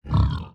animalia_pig_random.2.ogg